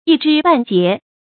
一肢半節 注音： ㄧ ㄓㄧ ㄅㄢˋ ㄐㄧㄝ ˊ 讀音讀法： 意思解釋： 比喻事物的一小部分。